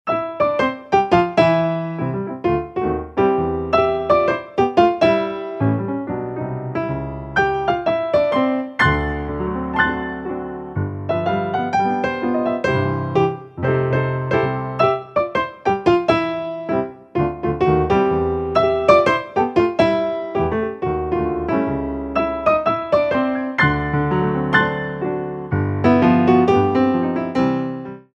entertainment pianists
A 1940's jazz standard